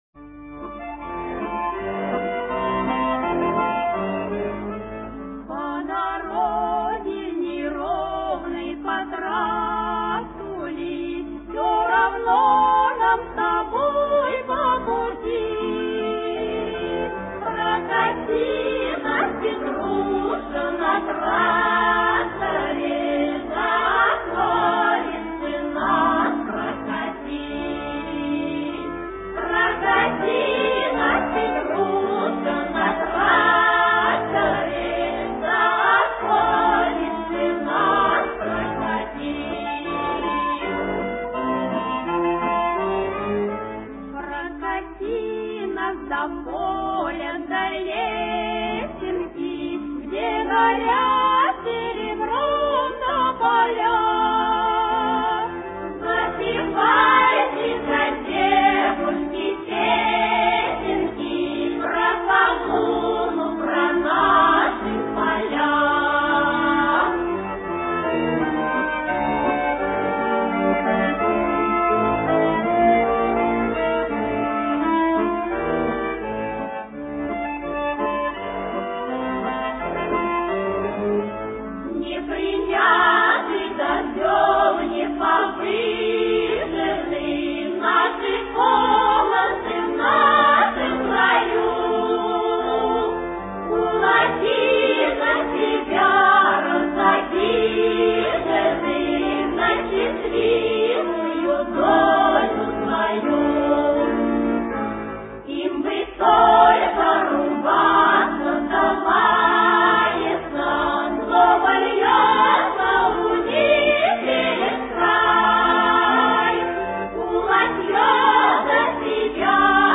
Тихая и печальная.